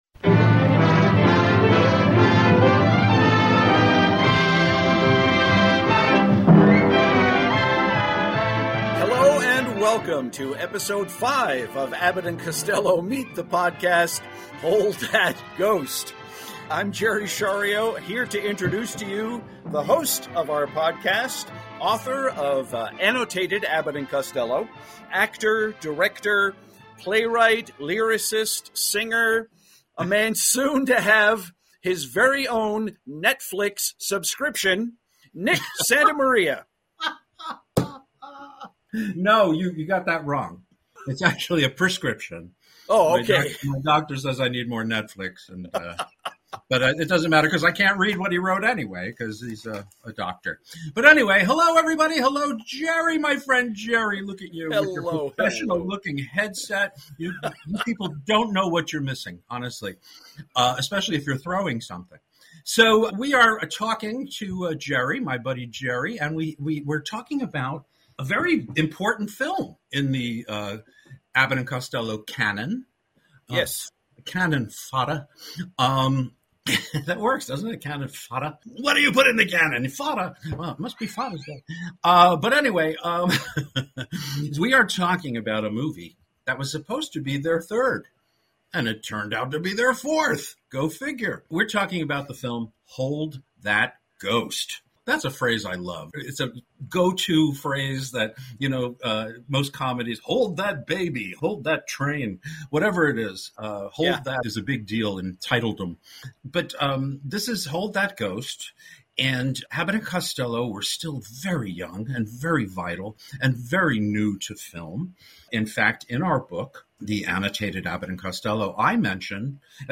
We also have a new theme song (on ukulele), and another rare clip from The Bud Abbott Variety Show.